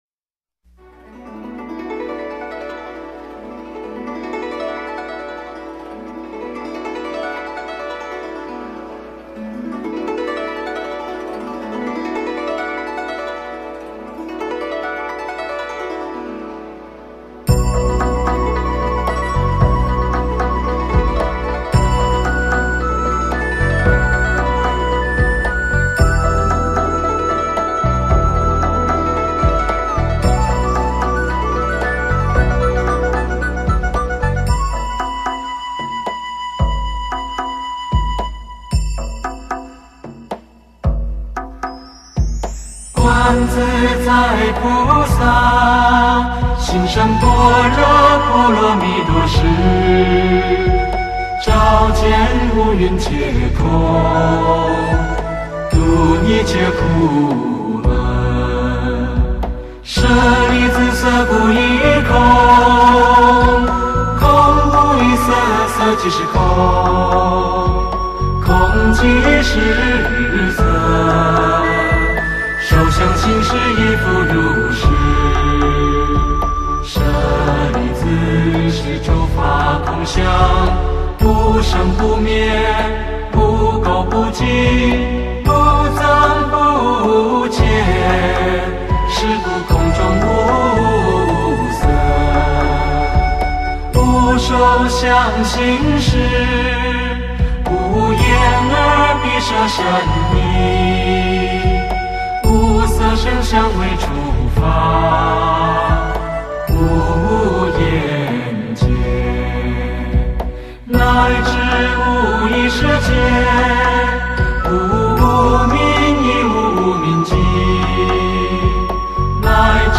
[16/7/2010]佛乐 — [ 般若波罗蜜多心经 ]（另一个版本也好听）